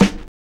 TEAR.wav